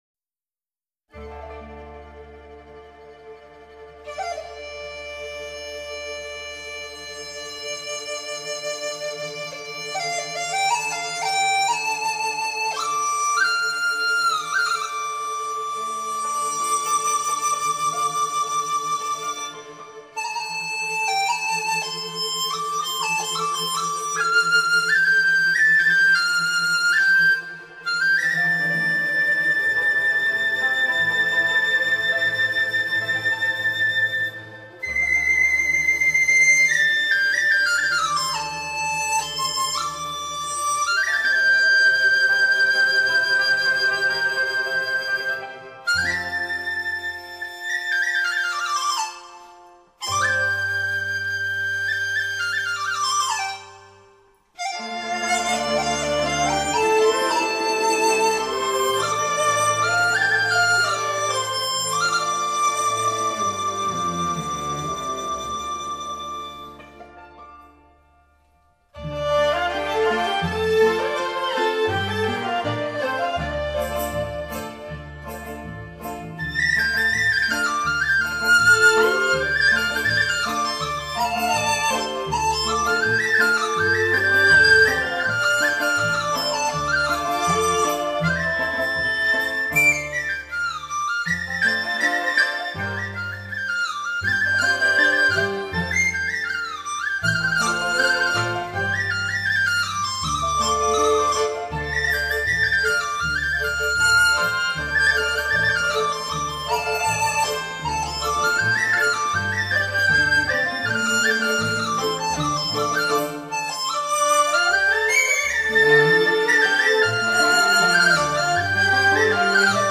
梆笛